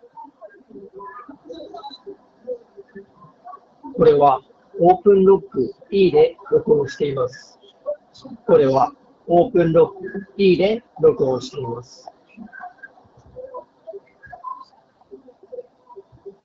スピーカーから雑踏音をそこそこ大きなボリュームで流しながらマイクで収録した音声がこちら。
ノイキャン効果が優秀。
マイクも僅かにこもり気味なものの悪くはないので、仕事でのちょっとした打ち合わせ程度であれば十分使えると思います。
openrock-e-voice.m4a